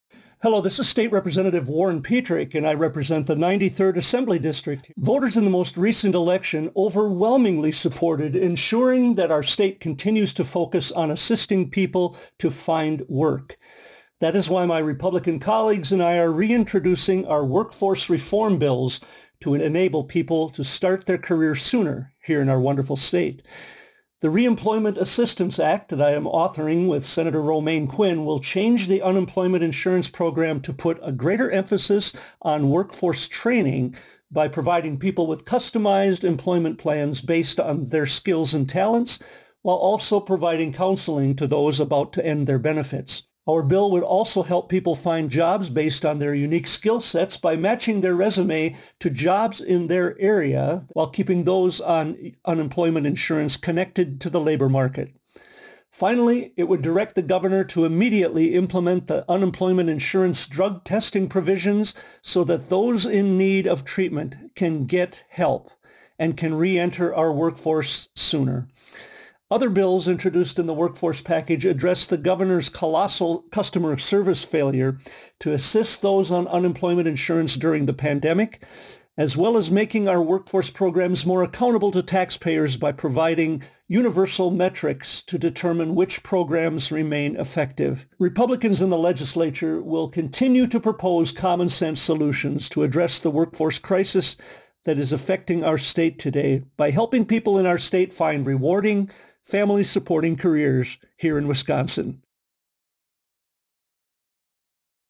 Republican Radio Address: Transcript &